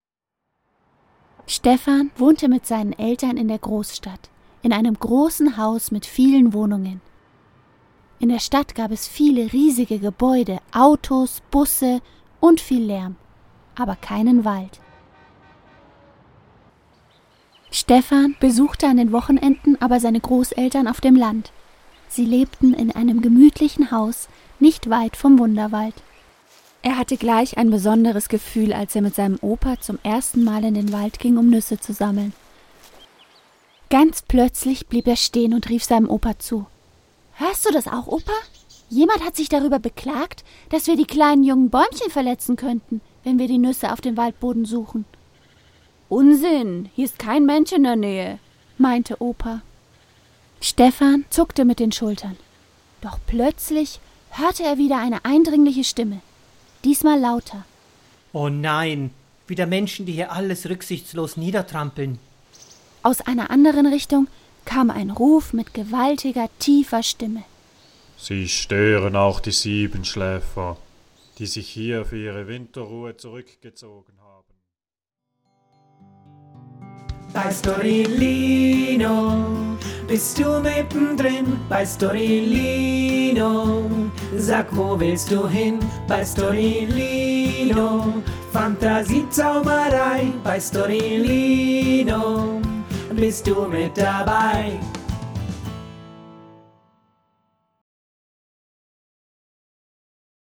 Ausschnitte aus der Hörgeschichte